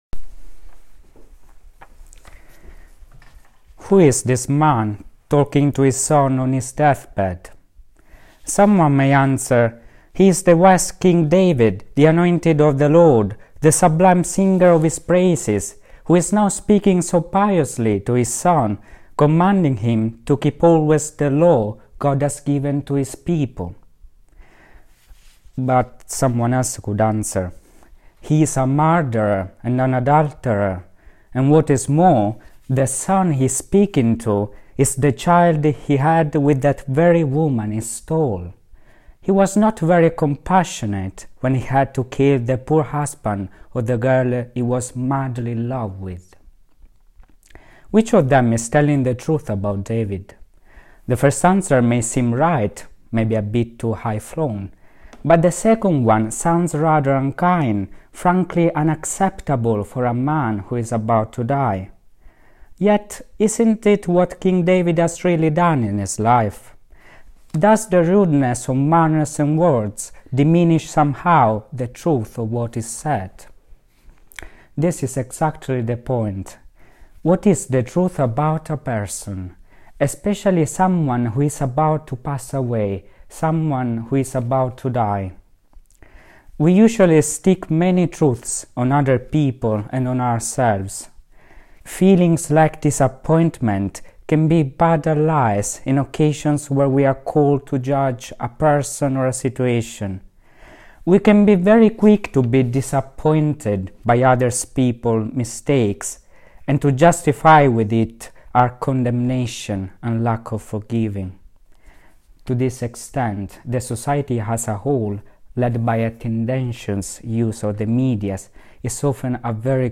This homily was preached to the student brothers during compline.